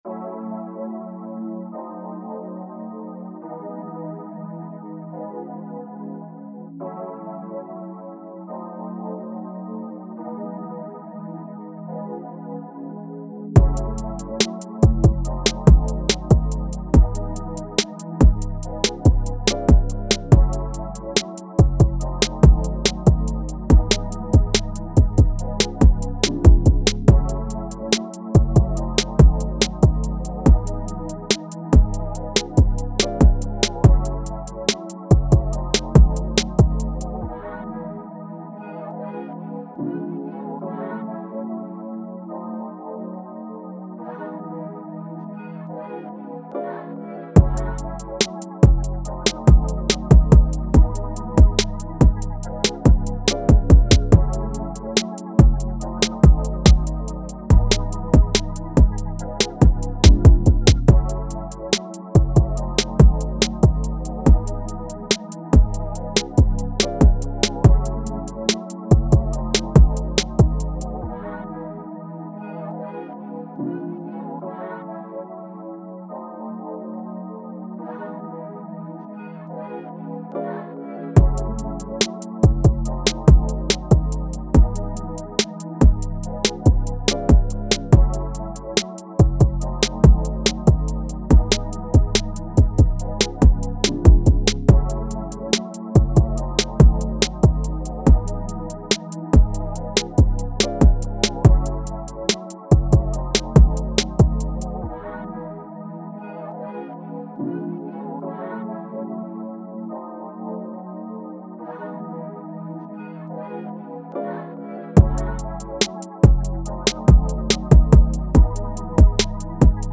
Hip Hop, Dance
C# Min